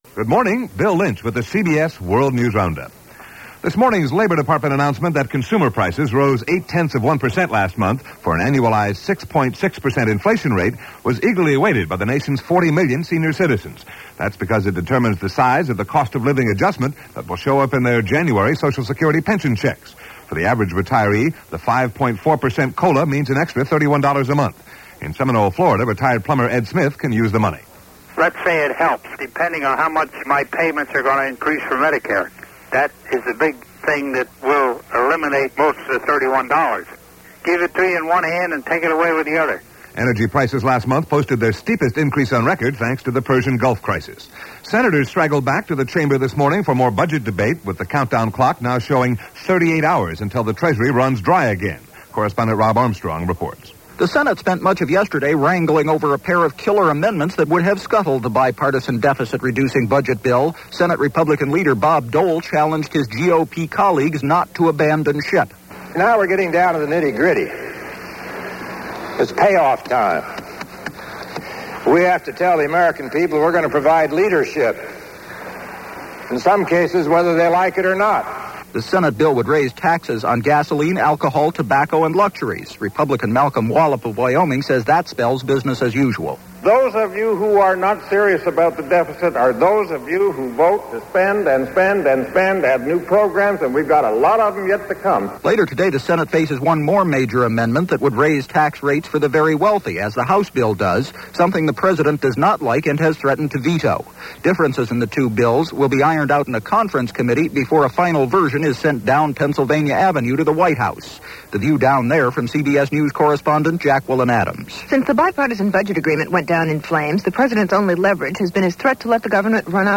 And that’s a little of what happened on this rather fractured and busy Thursday, October 18th in 1990, as presented by The CBS World News Roundup.